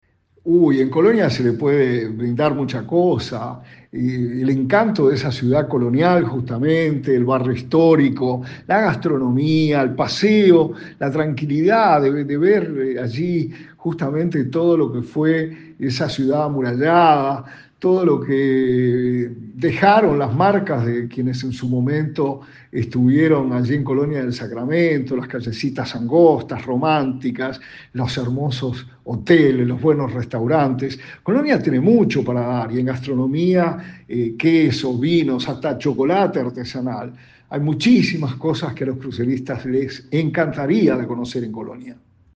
La información la dio a conocer el subsecretario de Turismo, Remo Monzeglio en diálogo con Radio del Oeste.